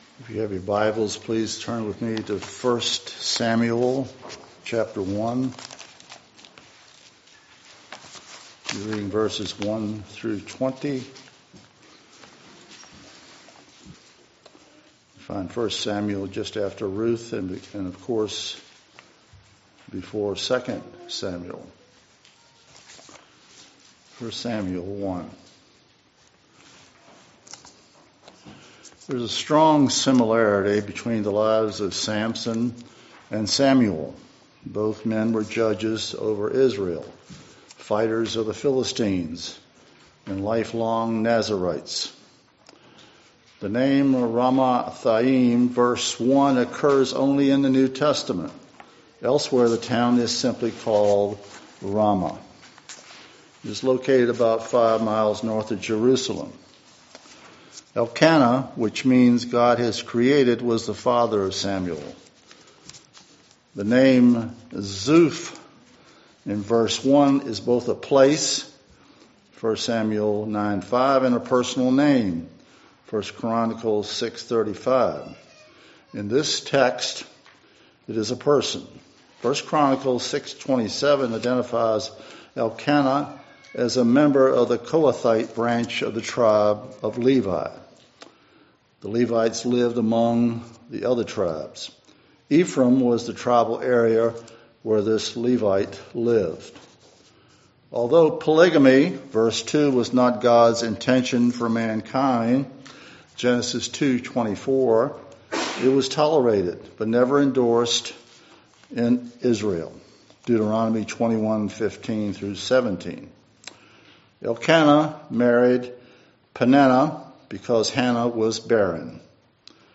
… continue reading 10 эпизодов # Religion # West # Suffolk # Baptist # Church # Reformed # West Suffolk Baptist Church # Christianity # Sermons # WSBC